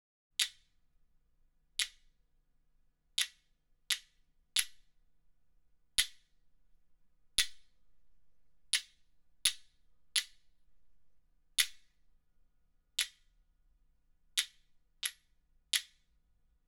They are used much like castanets with a pair of stones held in each hand.
Stones.wav